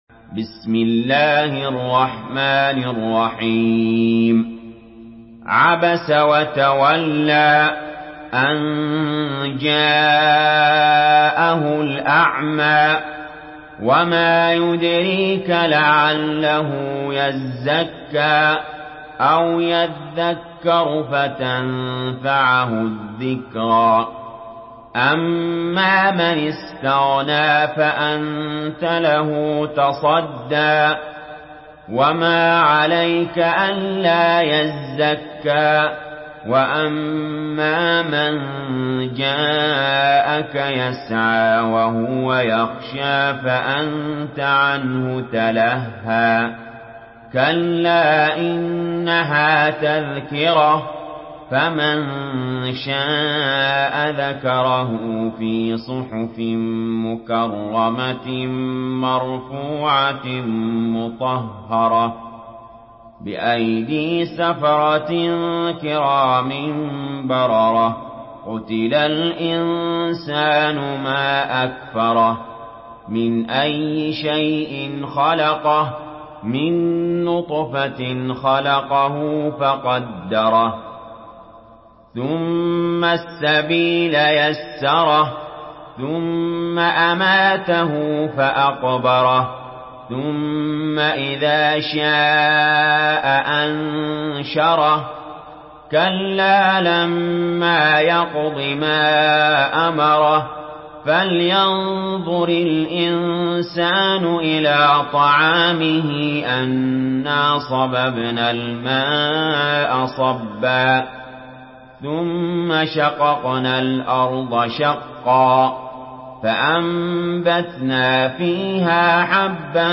Surah Abasa MP3 in the Voice of Ali Jaber in Hafs Narration
Murattal Hafs An Asim